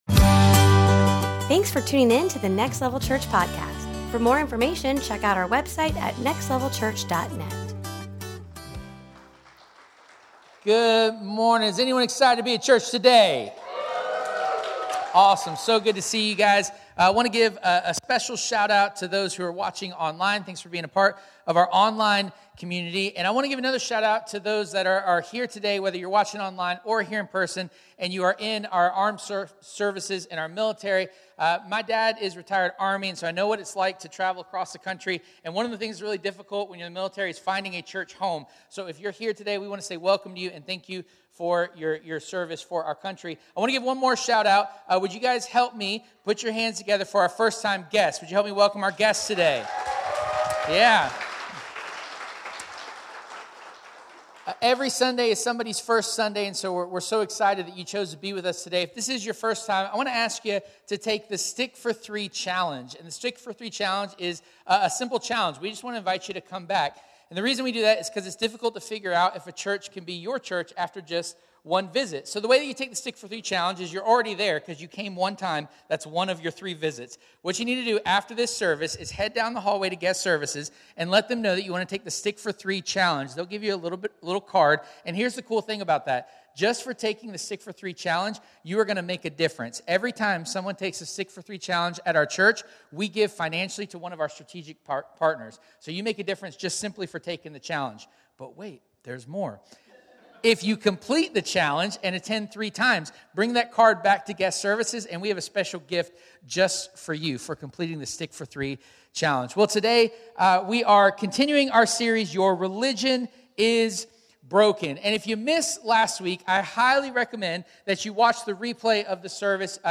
Your Religion is Broken Service Type: Sunday Morning « Your Religion is Broken